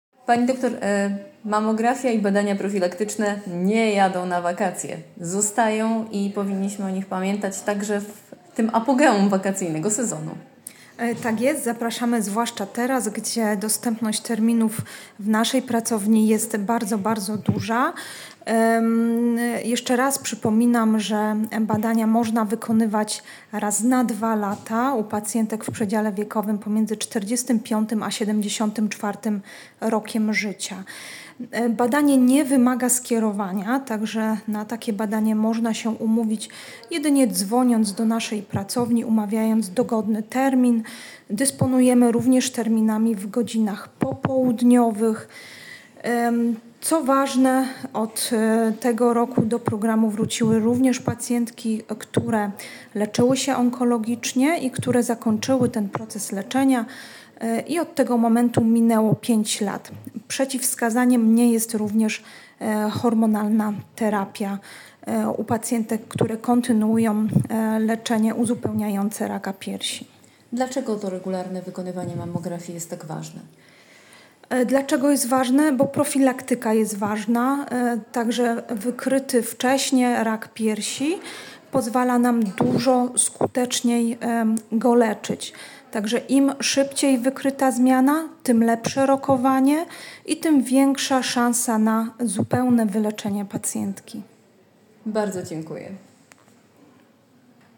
wywiadzie